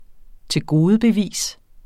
Udtale [ teˈgoːðəbeˌviˀs ]